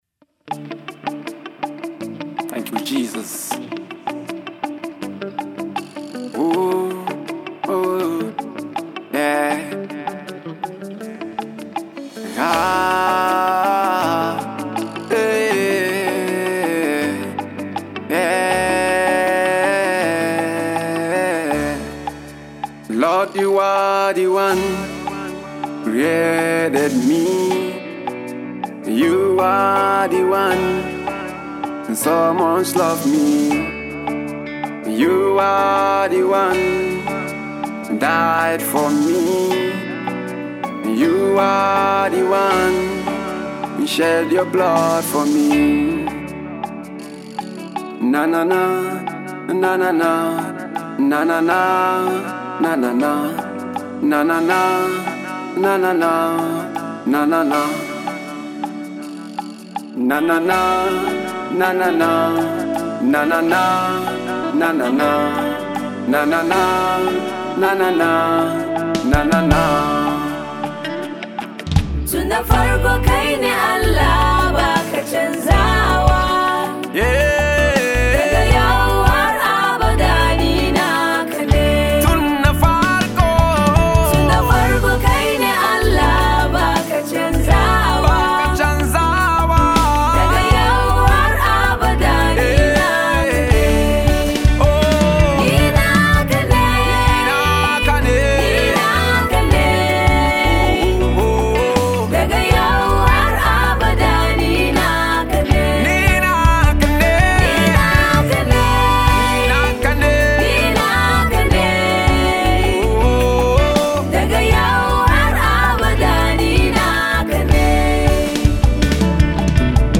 AlbumsNaija Gospel Songs